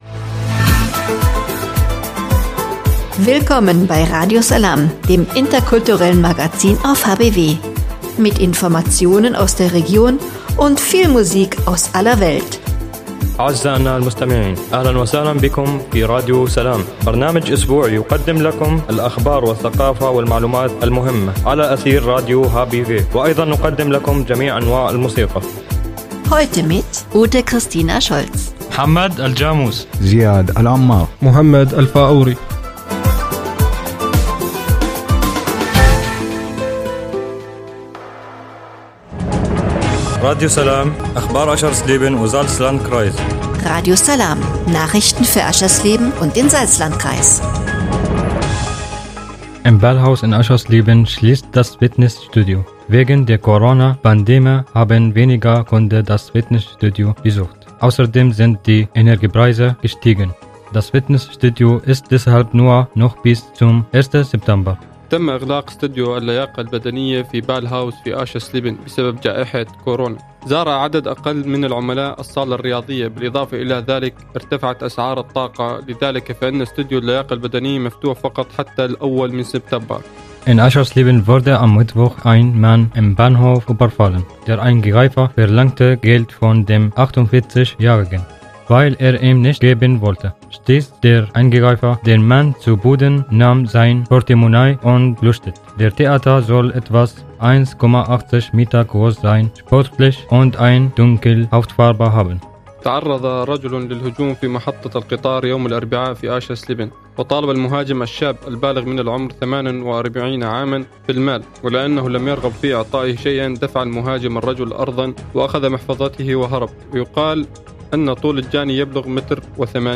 „Radio Salām“ heißt das interkulturelle Magazin auf radio hbw.
(Hinweis: Die in der Sendung enthaltene Musik wird hier in der Mediathek aus urheberrechtlichen Gründen weggelassen.)